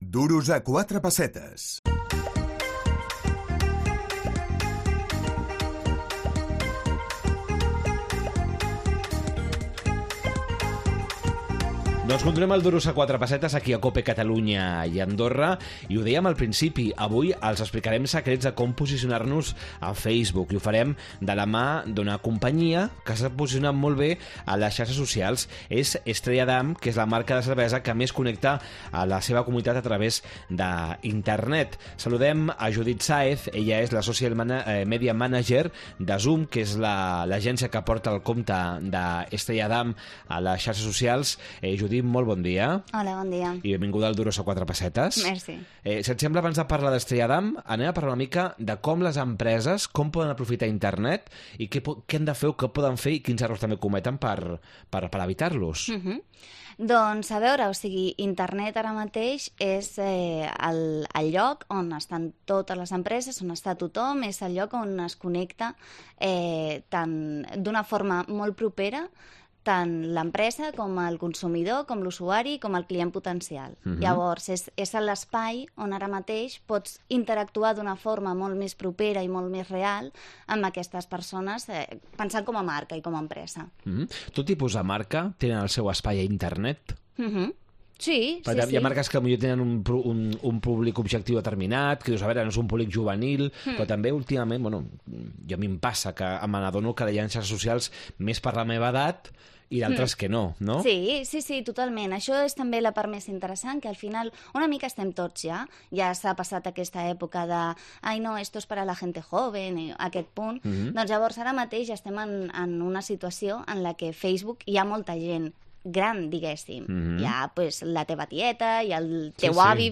Com podem treure el màxim profit de les xarxes socials? Coneixerem el cas d’èxit d’Estrella Damm. Entrevista